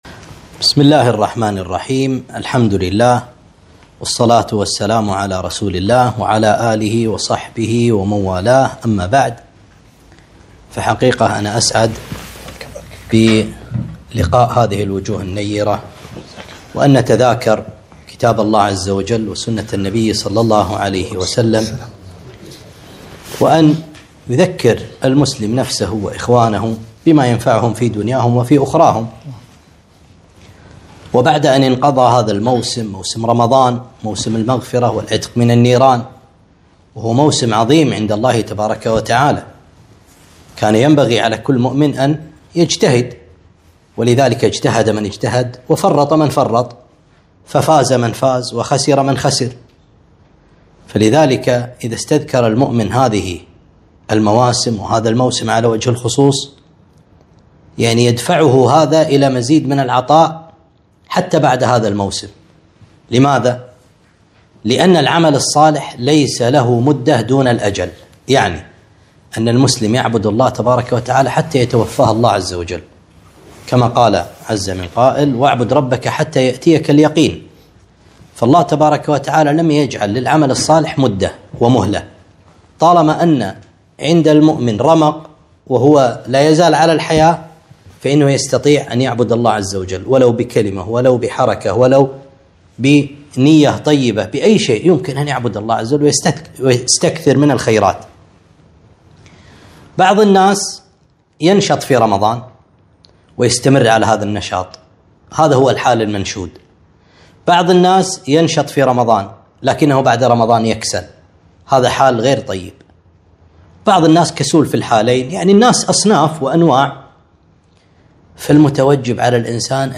كلمة - المؤمن بعد رمضان